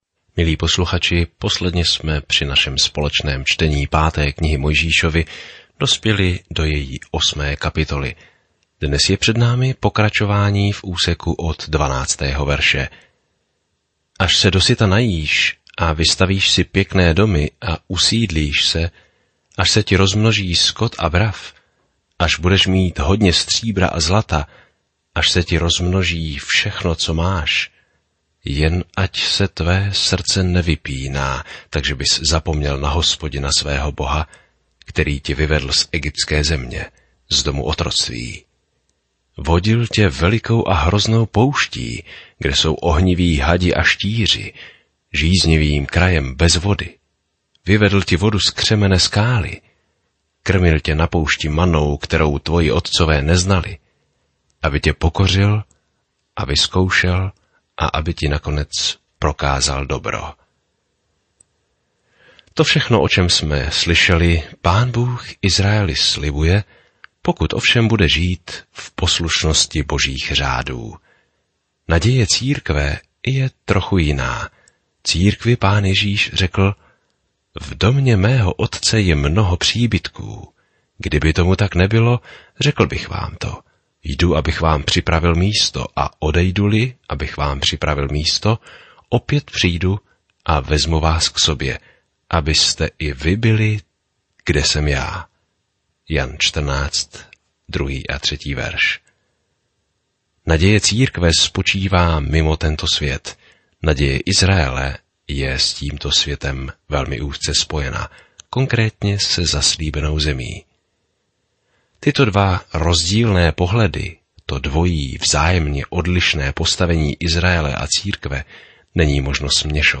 Písmo Deuteronomium 8:12-20 Deuteronomium 9:1-4 Den 10 Začít tento plán Den 12 O tomto plánu Deuteronomium shrnuje dobrý Boží zákon a učí, že poslušnost je naší odpovědí na jeho lásku. Denně procházejte Deuteronomium a poslouchejte audiostudii a čtěte vybrané verše z Božího slova.